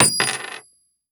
Add ghost sounds.
drain.LN50.pc.snd.wav